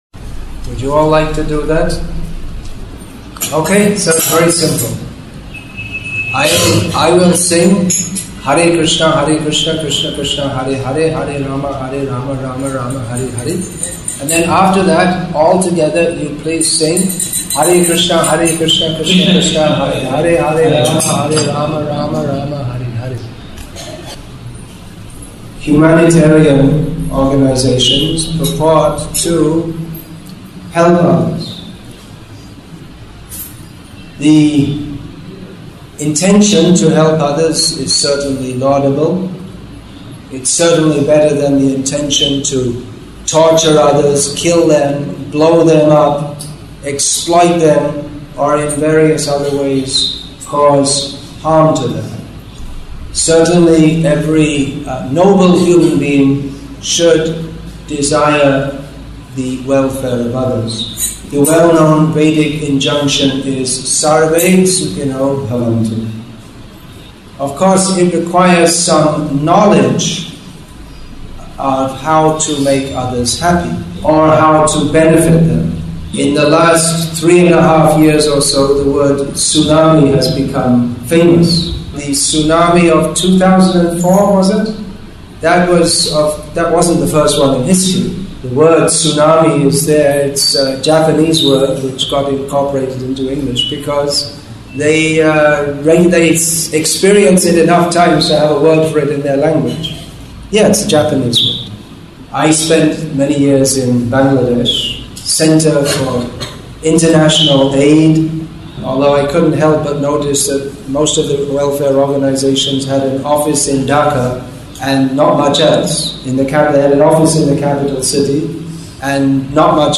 Beyond humanitarianism February 25, 2008 Assorted Lectures Philosophy , Preaching , Social Issues English with தமிழ் (Tamil) Translation; Akkaraipattu, Tamil Nadu , India 1 h 44 m 1 s 49.93 MB Download Play Add To Playlist Download